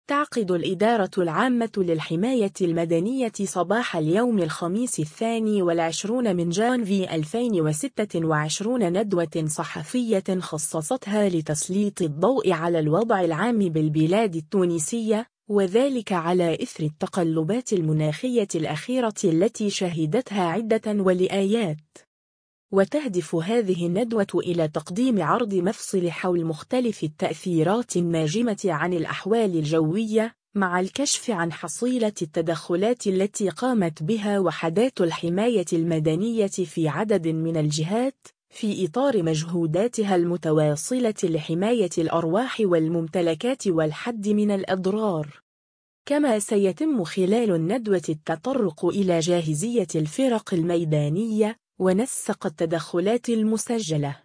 تعقد الإدارة العامة للحماية المدنية صباح اليوم الخميس 22 جانفي 2026 ندوة صحفية خصصتها لتسليط الضوء على الوضع العام بالبلاد التونسية، وذلك على إثر التقلبات المناخية الأخيرة التي شهدتها عدة ولايات.